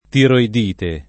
[ tiroid & te ]